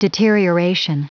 Prononciation du mot deterioration en anglais (fichier audio)
Prononciation du mot : deterioration